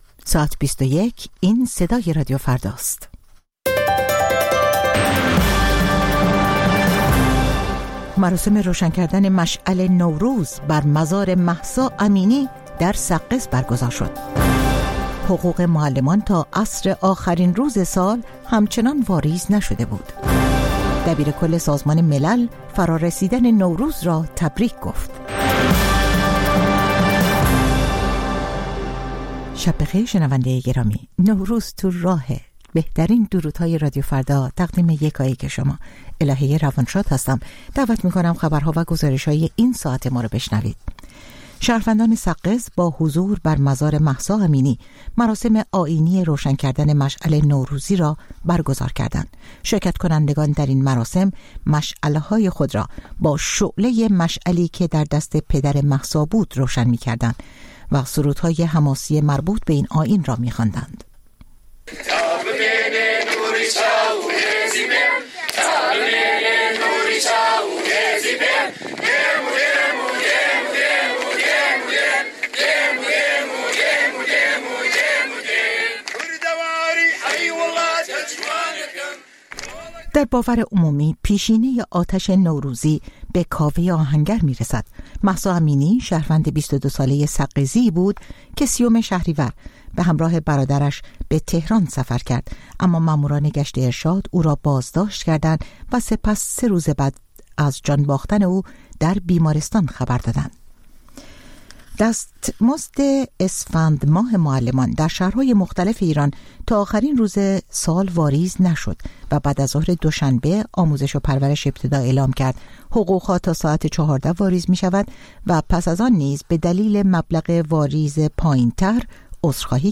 خبرها و گزارش‌ها ۲۱:۰۰